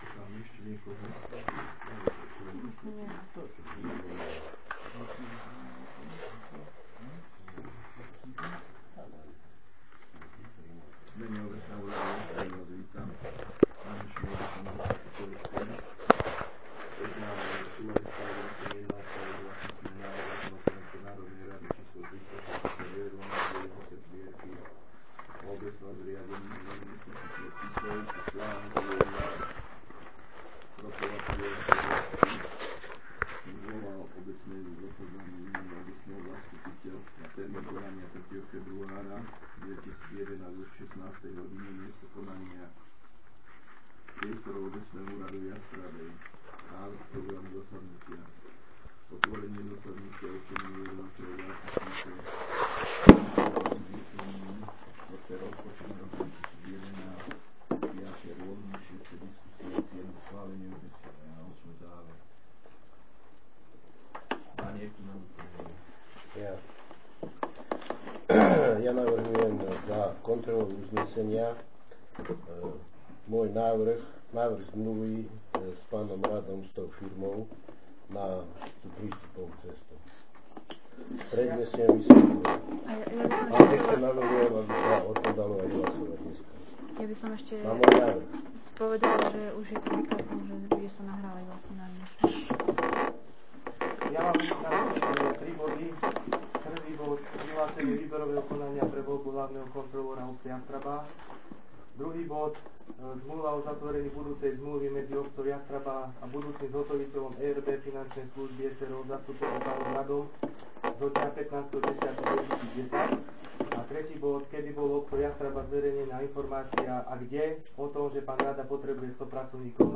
Zvukový záznam zasadnutia z 03.02.2011